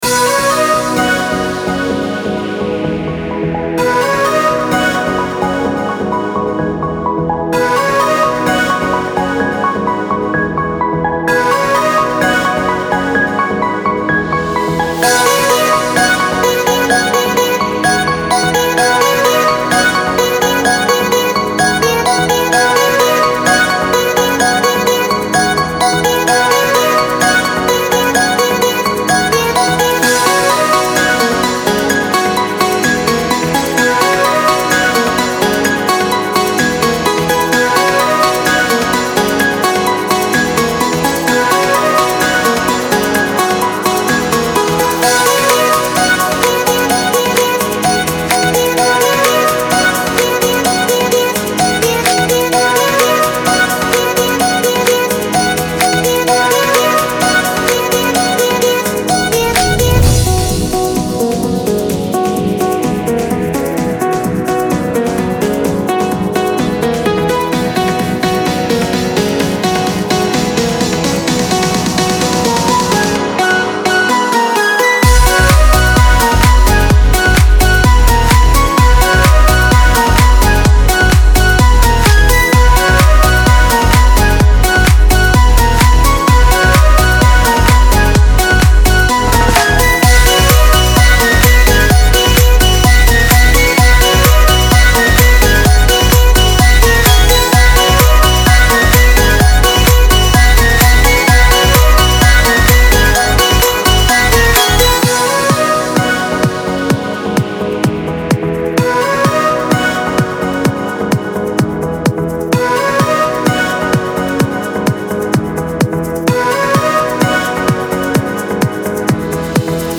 موسیقی کنار تو
موسیقی بی کلام الکترونیک پاپ